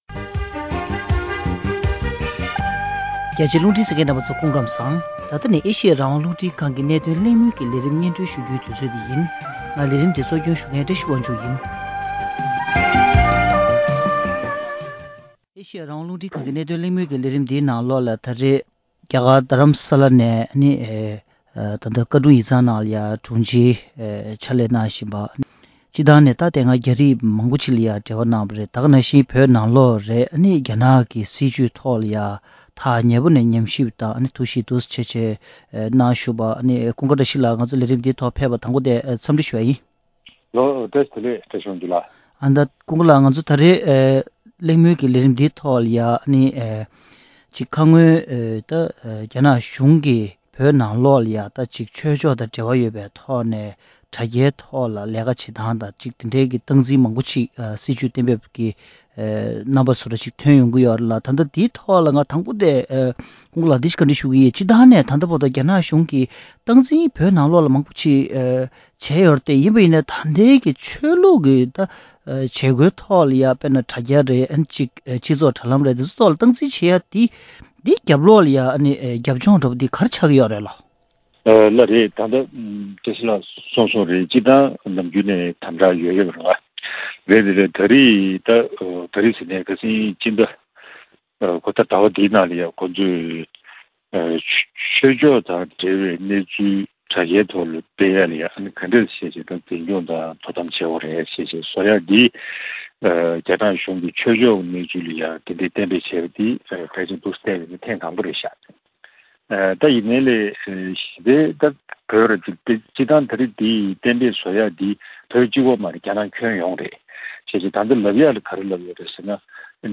རྒྱ་ནག་གཞུང་གིས་ཆོས་ཕྱོགས་དང་འབྲེལ་བའི་གནས་ཚུལ་དྲ་རྒྱའི་ཐོག་སྤེལ་རྒྱུར་དོ་དམ་བྱ་ཐབས་ཀྱི་སྒྲིག་གཞི་གཏན་འབེབས་བྱ་འཆར་ཡོད་པའི་ཐད་གླེང་མོལ།